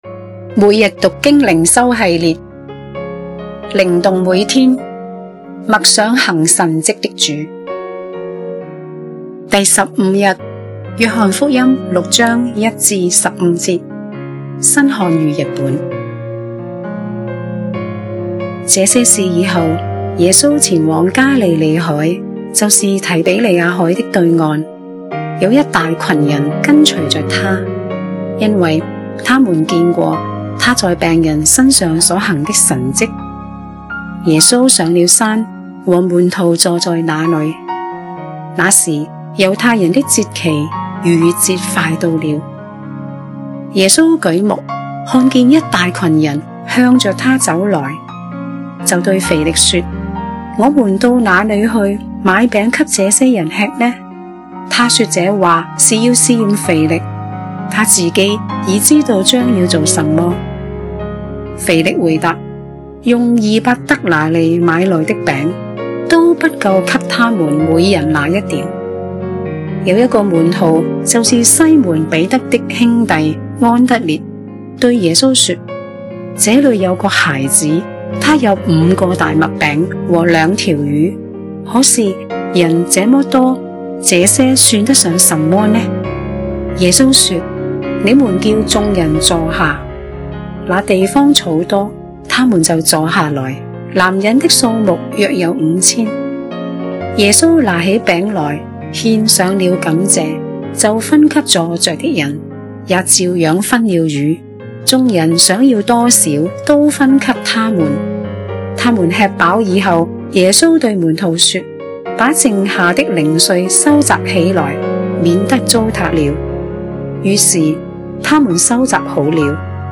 閱讀經文：約6:1-15 新漢語 1 這些事以後，耶穌前往加利利海——就是提比哩亞海——的對岸。